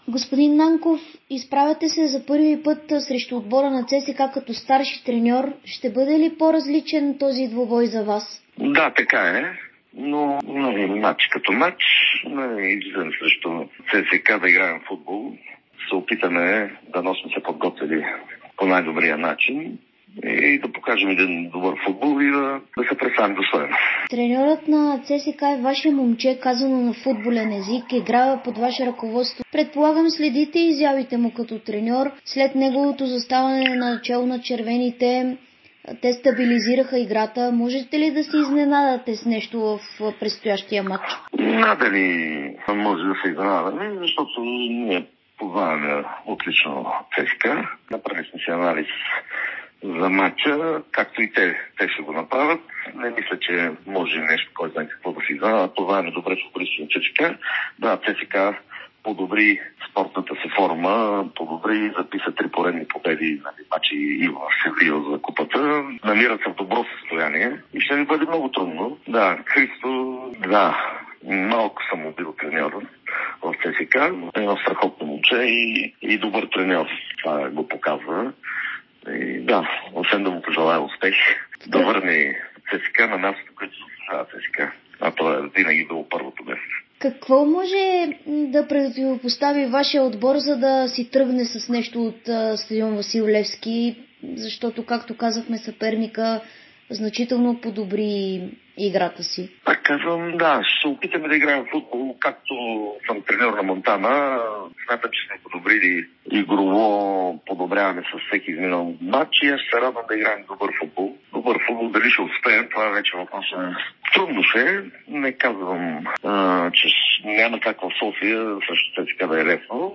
Старши треньорът на Монтана - Анатоли Нанков, говори за Дарик радио и Dsport, преди гостуването на ЦСКА от 14-ия кръг на Първа лига.